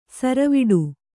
♪ saraviḍu